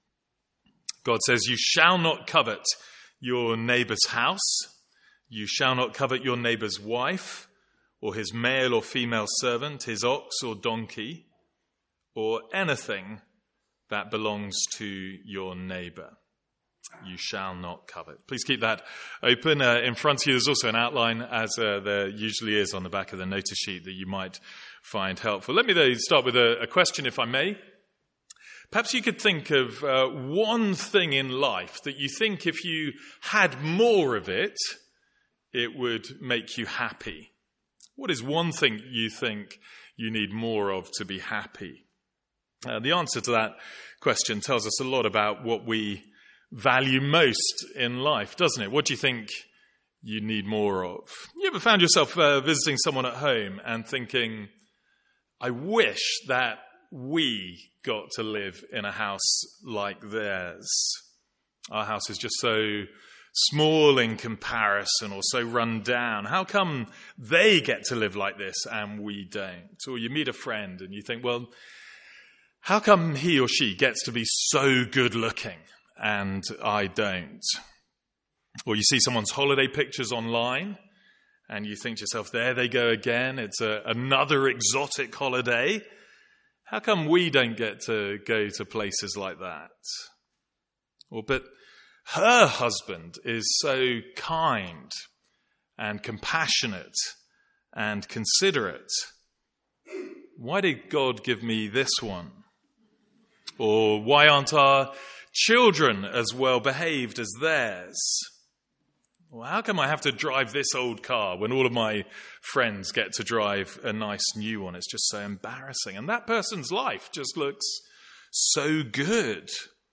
Sermons | St Andrews Free Church
From our morning series in the Ten Commandments.